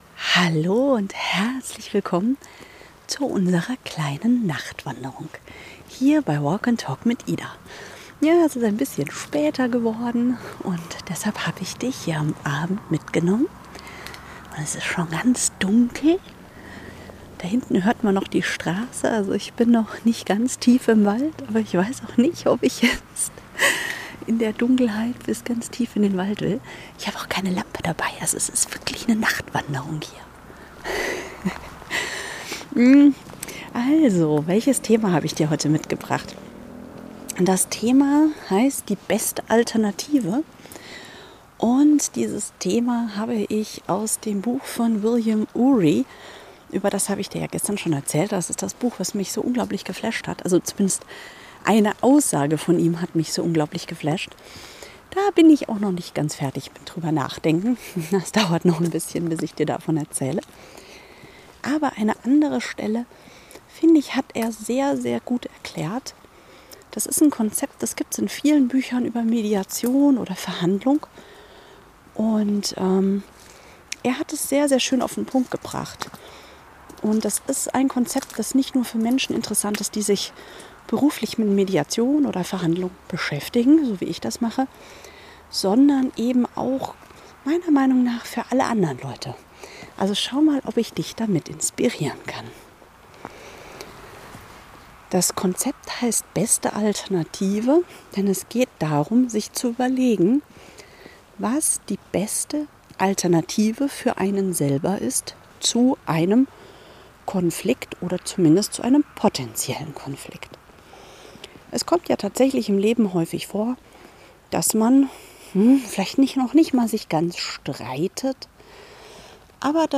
Ich bin heute im Dunkeln unterwegs – Nachtwanderung ohne Lampe,